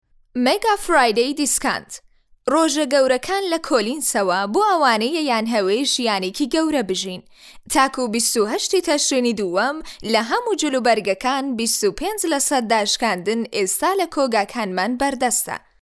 Female
Young
Commercial